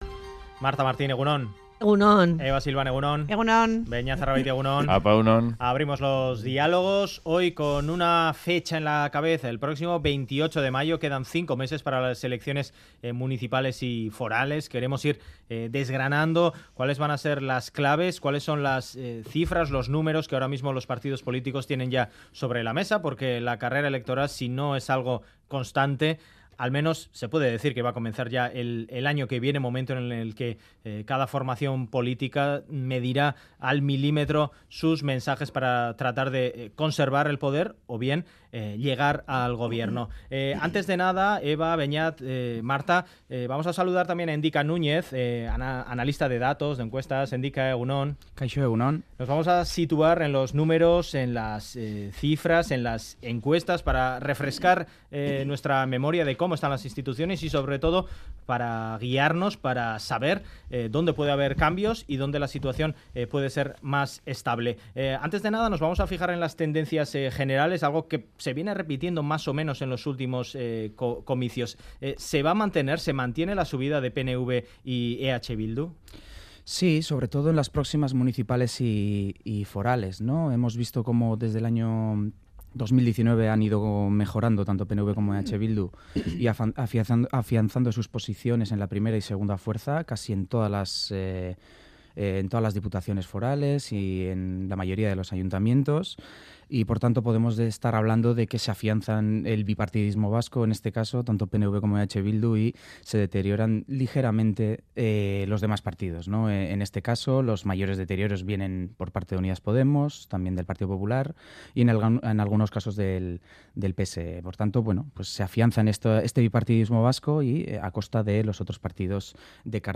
El 28 mayo habrá elecciones municipales y forales en la Comunidad Autónoma vasca. Los colaboradores de "Boulevard" analizan los datos publicados por el sociometro en Radio Euskadi.